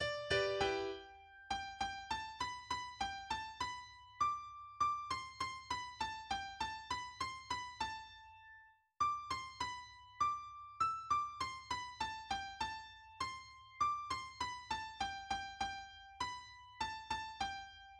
\key g \major
\time 5/4
Das Bürgerlied (auch Ob wir rote, gelbe Kragen oder Königsberger Volkslied) ist ein 1845 von Adalbert Harnisch (1815–1889) geschriebenes Volkslied.